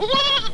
Baby Lamb Sound Effect
Download a high-quality baby lamb sound effect.
baby-lamb.mp3